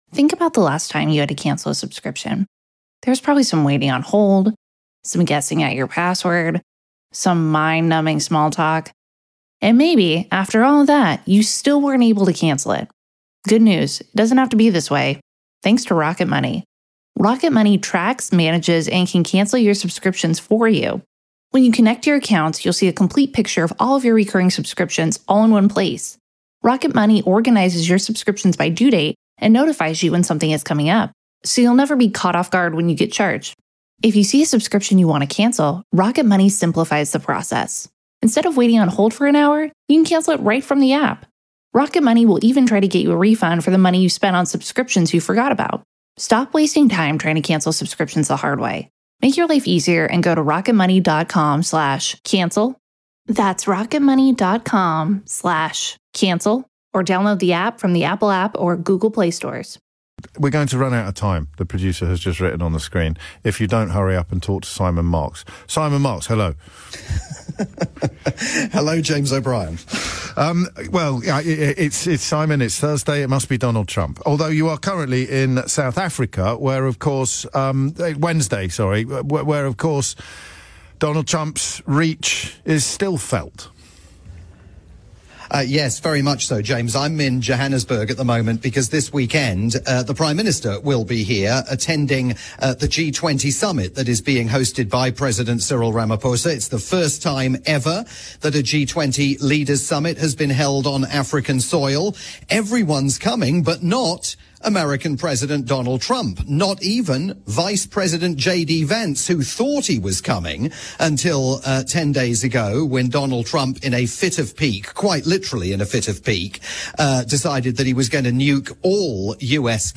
live US roundup for James O'Brien's morning programme on the UK's LBC.